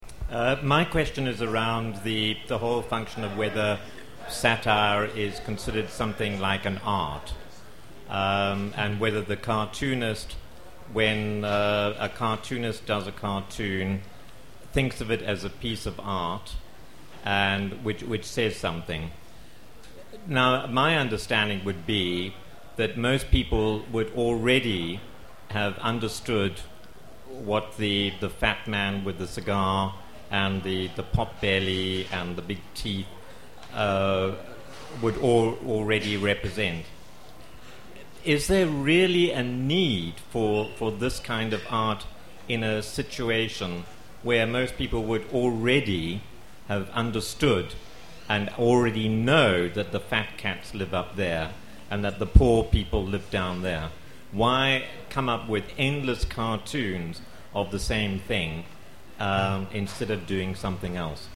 Satire - Book Café discussion
at a discussion on satire at Harare's Book Café on Thursday 27 November